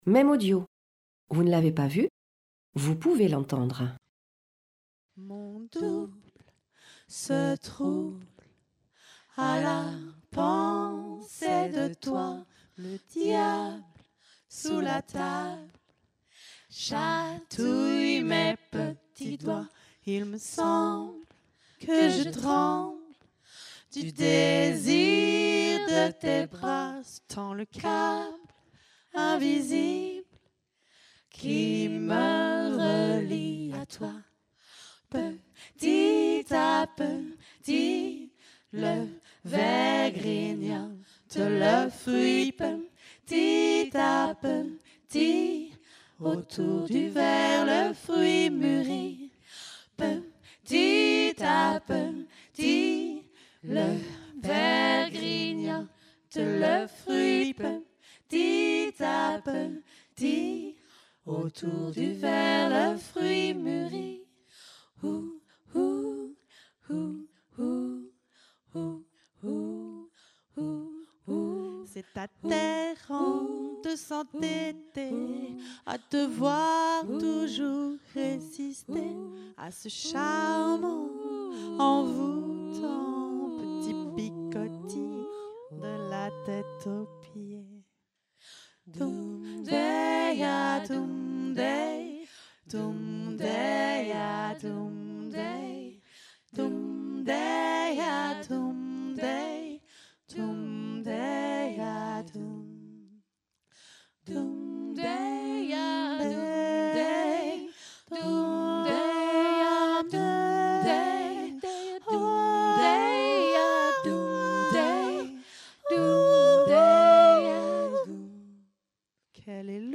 Théâtre Le fil à plomb, Toulouse